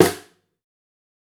TC2 Snare 7.wav